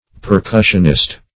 percussionist \per*cus"sion*ist\ (p[~e]r*k[u^]sh"[u^]n*[i^]st),